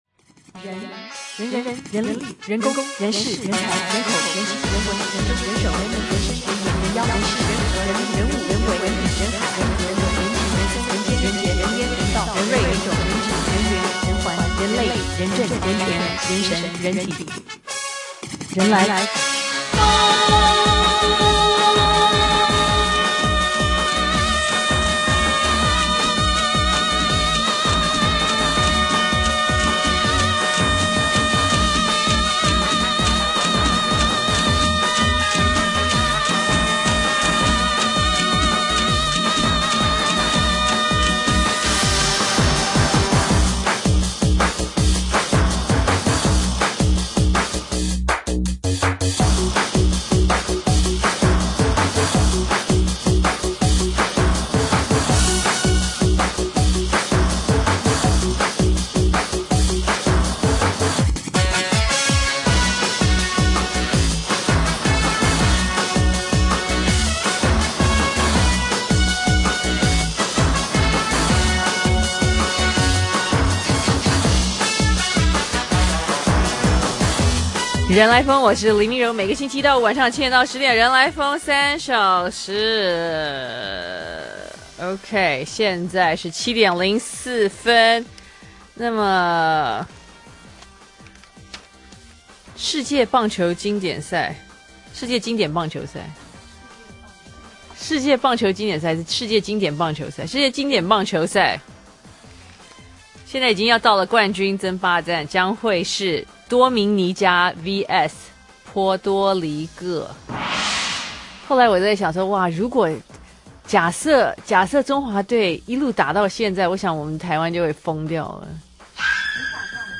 專訪